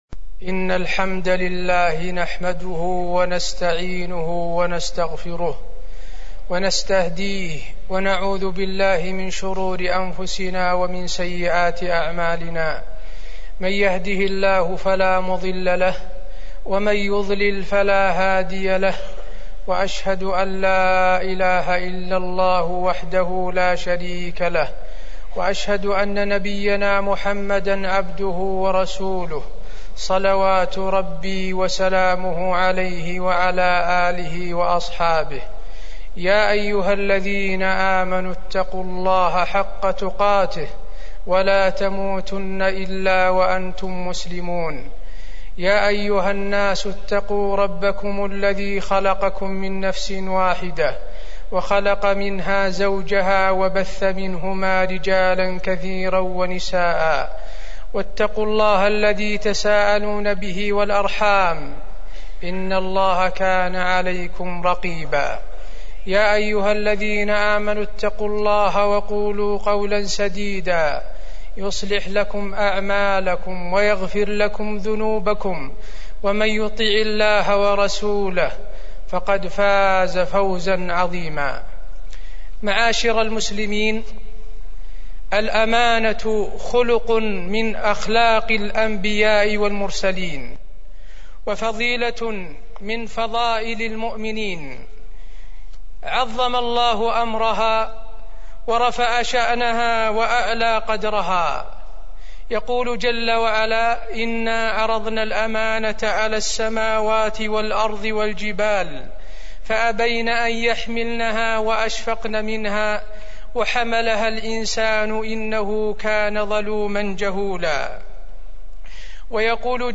تاريخ النشر ١٣ ربيع الأول ١٤٢٦ هـ المكان: المسجد النبوي الشيخ: فضيلة الشيخ د. حسين بن عبدالعزيز آل الشيخ فضيلة الشيخ د. حسين بن عبدالعزيز آل الشيخ الأمانة